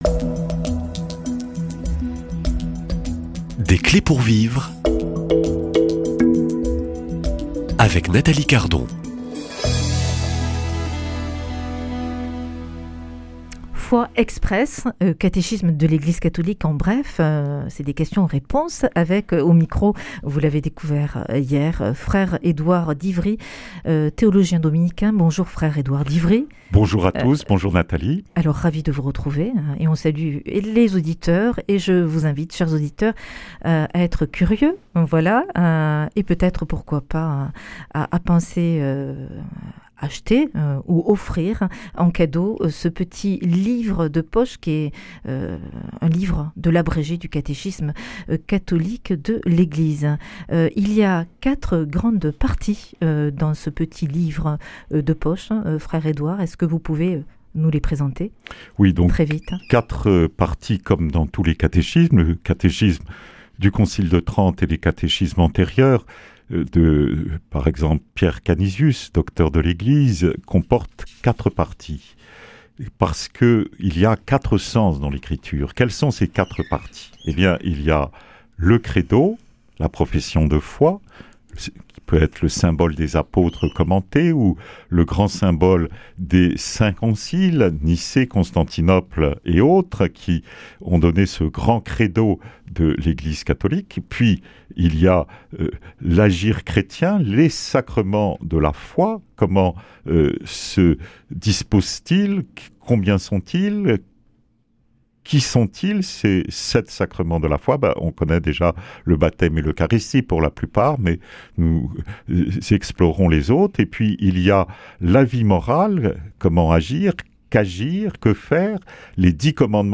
Invité